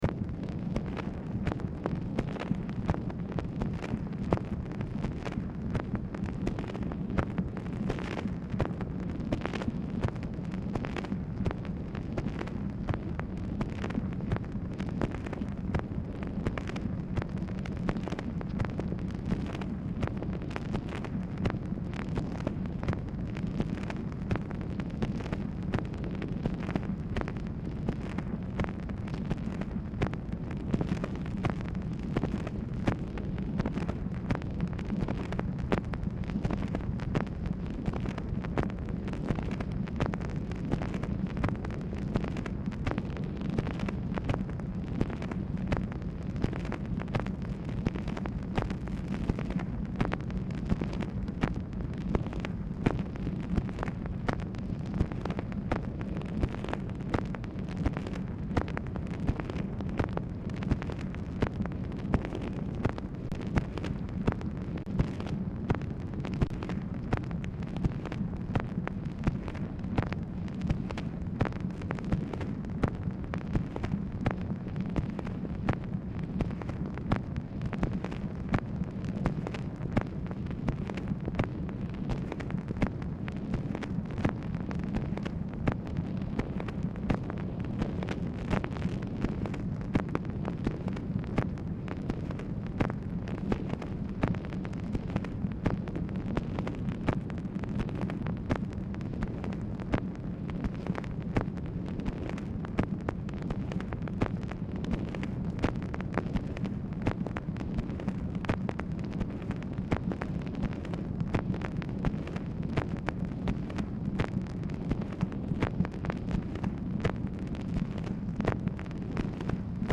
Telephone conversation # 8364, sound recording, MACHINE NOISE, 7/20/1965, time unknown | Discover LBJ
Format Dictation belt